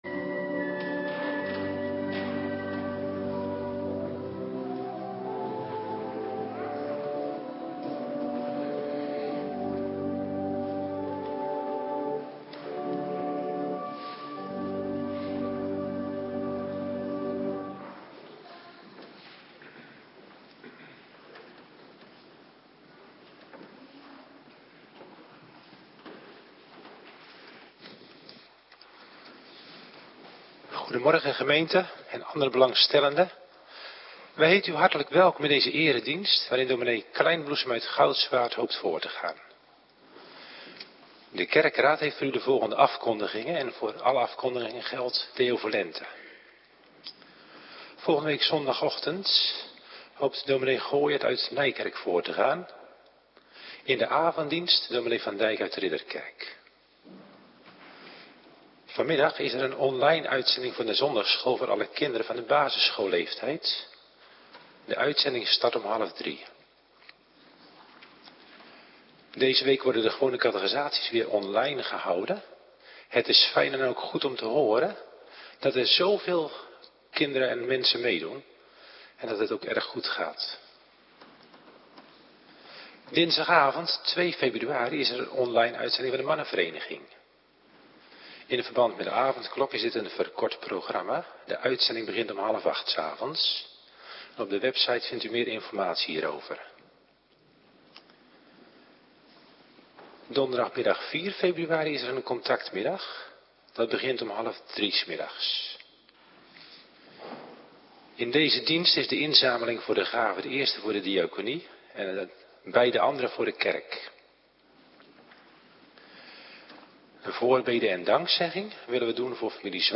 Morgendienst - Cluster 2
Locatie: Hervormde Gemeente Waarder
Preek 1 Korinthe 2